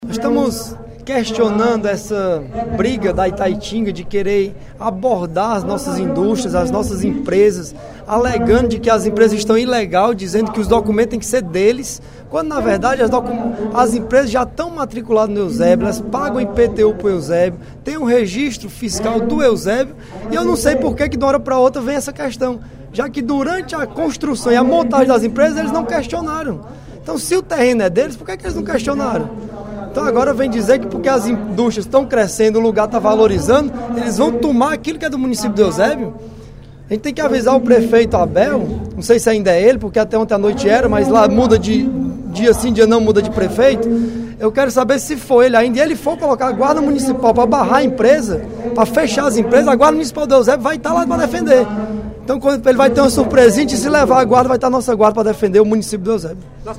O deputado Bruno Gonçalves (PEN) comentou, durante o primeiro expediente da sessão plenária da Assembleia Legislativa desta quarta-feira (11/02), a questão entre as prefeituras de Eusébio e Itaitinga envolvendo um terreno localizado nas margens do riacho Carro Quebrado.